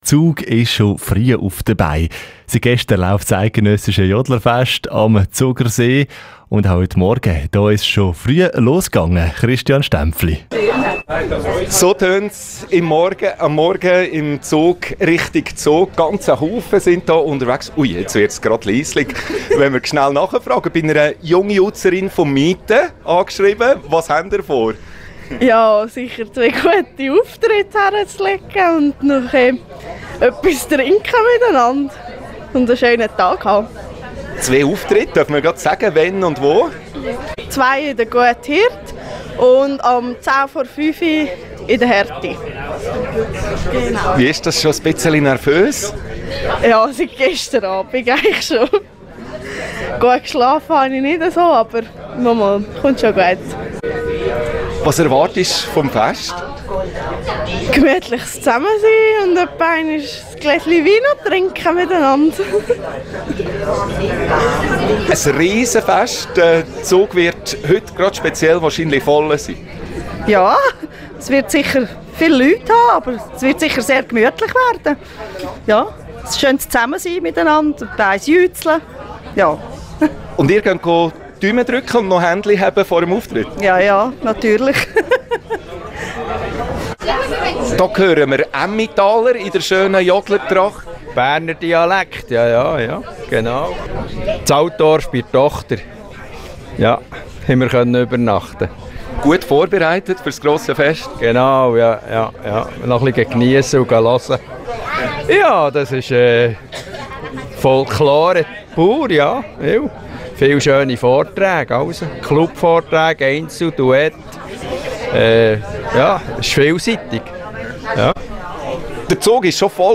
Report Radio Central 06/23